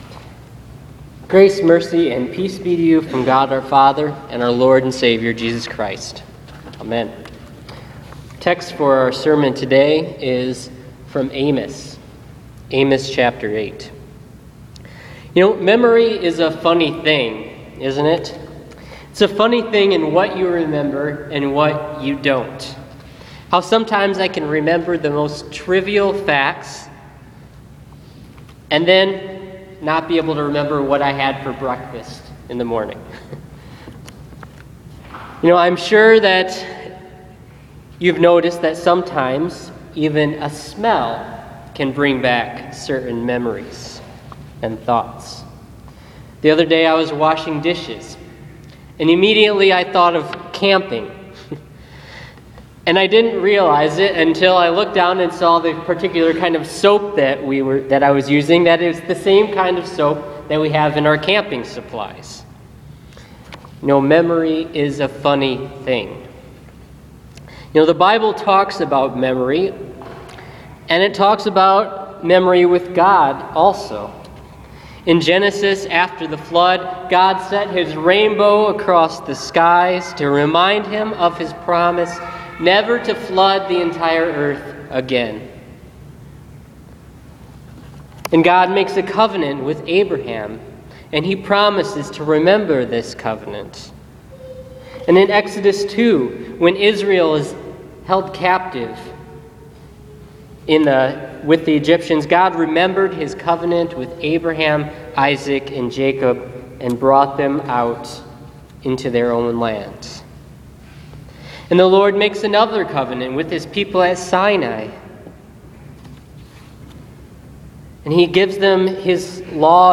Listen to this week’s sermon for the 20th Sunday after Pentecost.